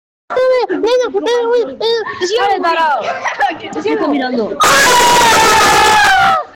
El Grito De Tinky Winky Sound Effects Free Download